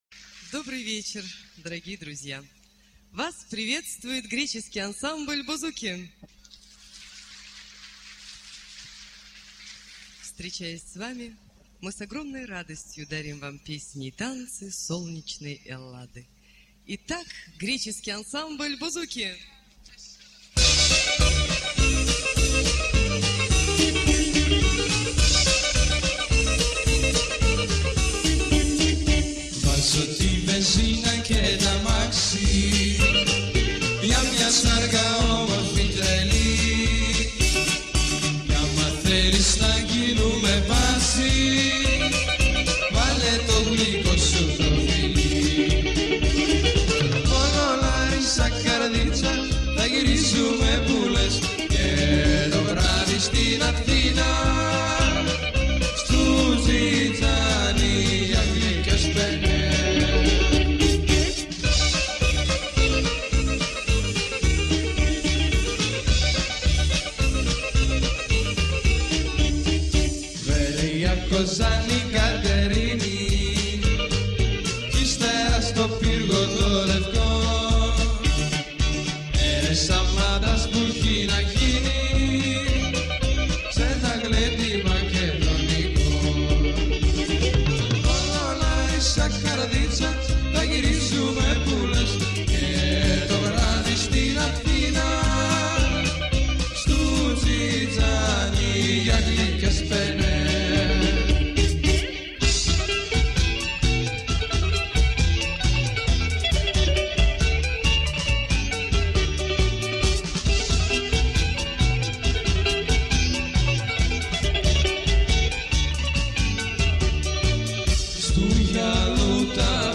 Бузуки-Bouzouki - Музыкальные Видеоклипы - ГРЕЧЕСКАЯ МУЗЫКА - Музыка народов мира.